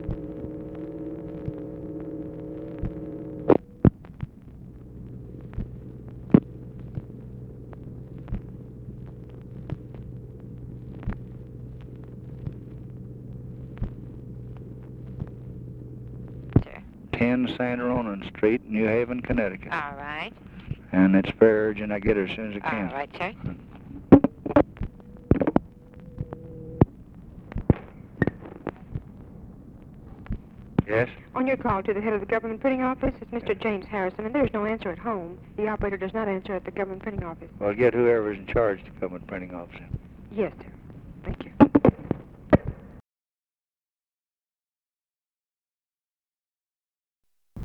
Conversation with TELEPHONE OPERATOR
Secret White House Tapes | Lyndon B. Johnson Presidency